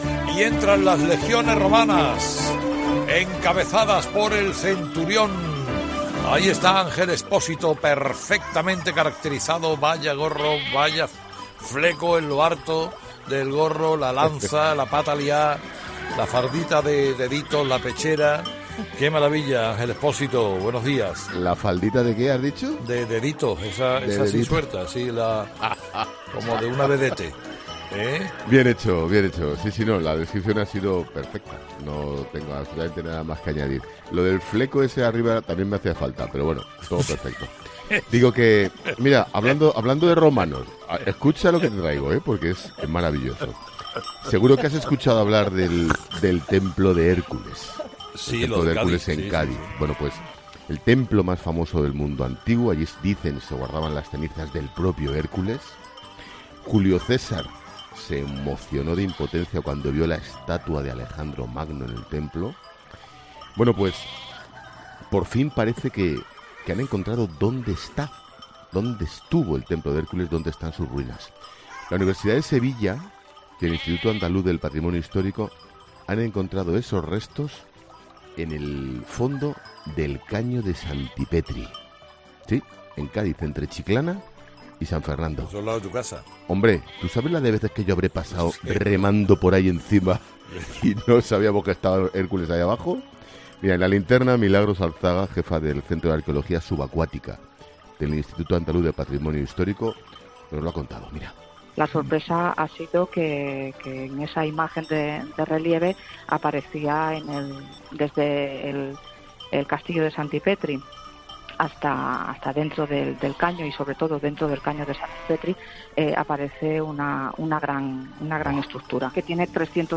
Ángel Expósito hace su paseíllo en 'Herrera en COPE'.